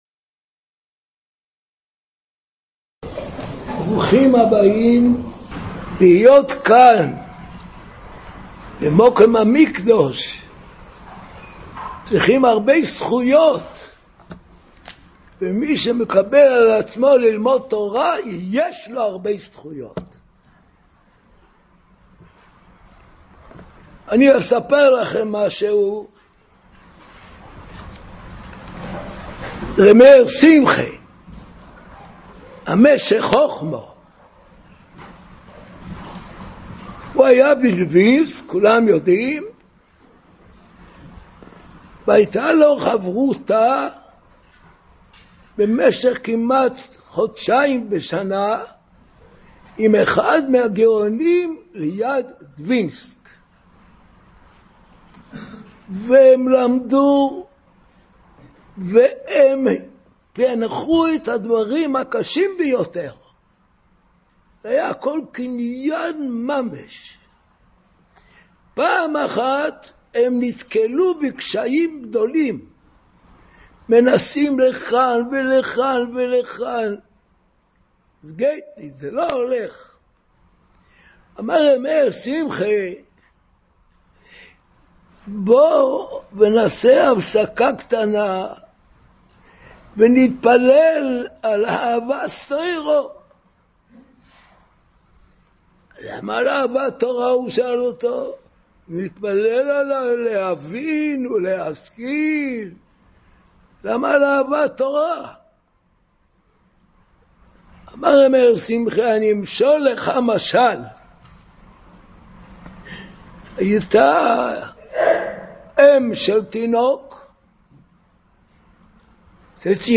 09 Yarchei Kallah 2025 - מעריב - מסע תפילה and דברי התעררות at the כותל המערבי מול מקום קודש הקדשים - Yeshivas Mir Yerushalayim
Shiurim